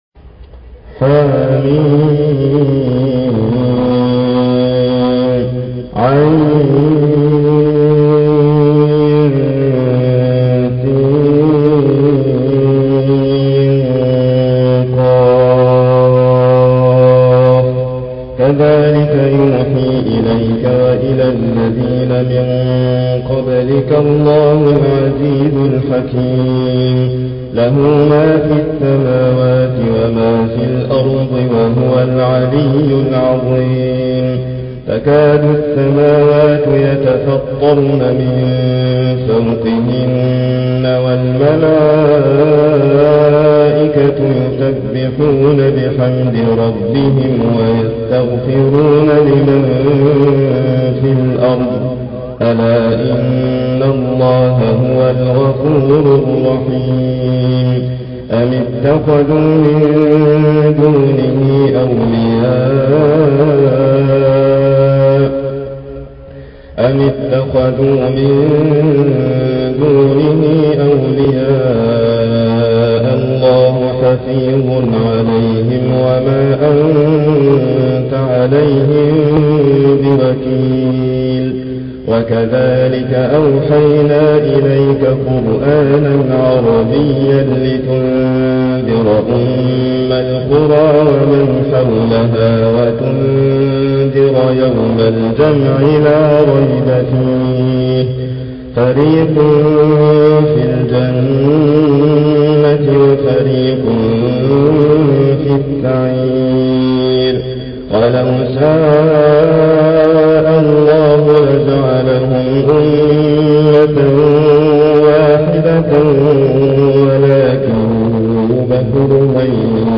تحميل : 42. سورة الشورى / القارئ حاتم فريد الواعر / القرآن الكريم / موقع يا حسين